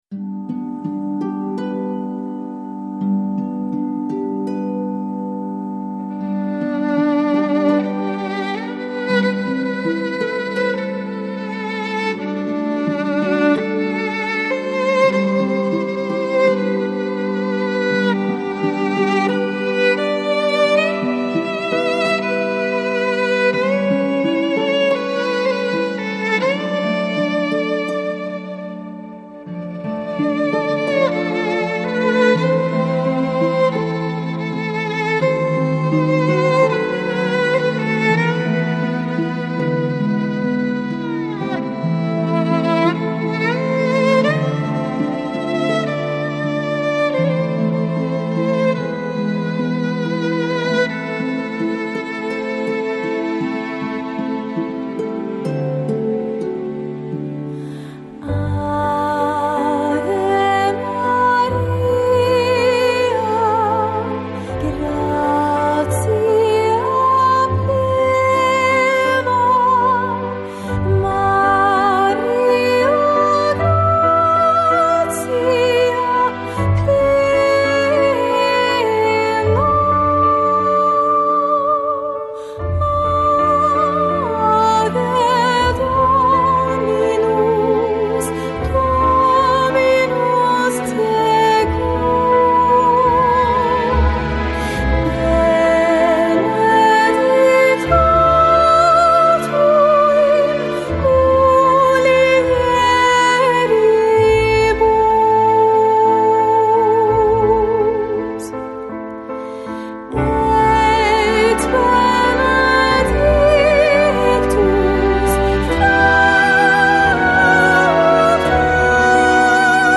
FLAC Жанр: Classical, Folk Издание